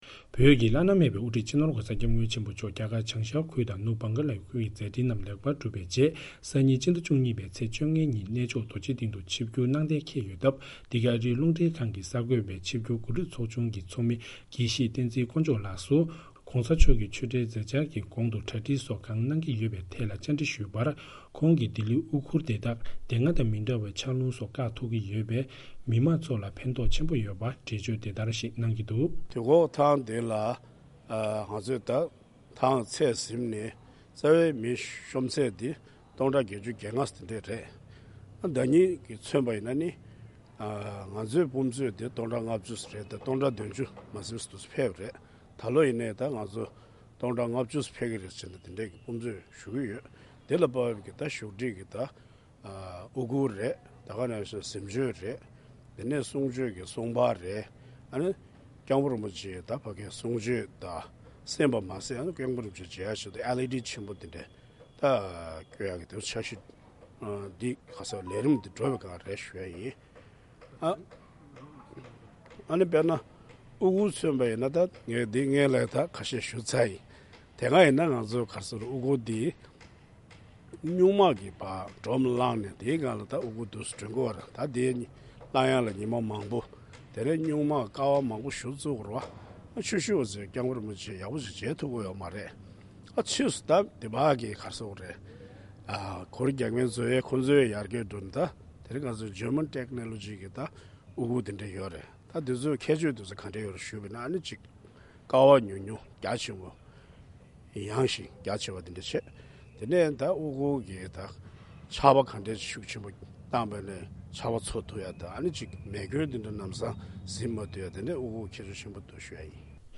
བཅར་འདྲི་ཞུས་པ།